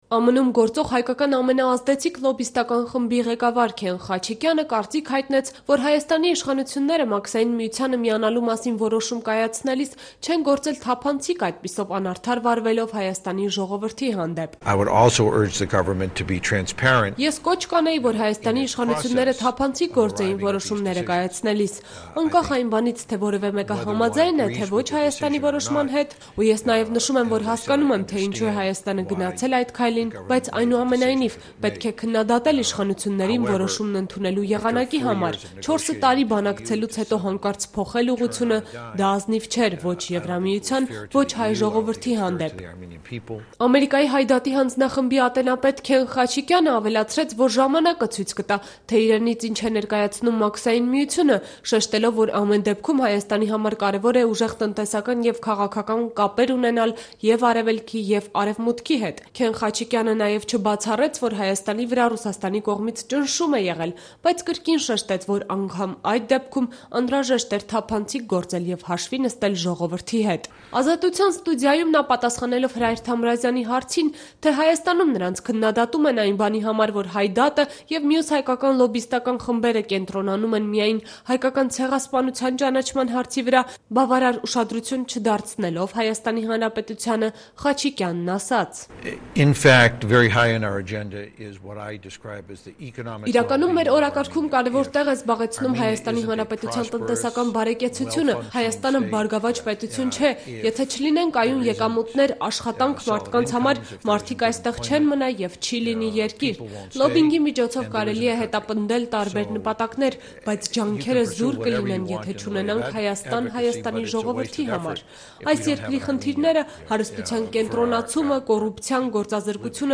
Հարցազրույց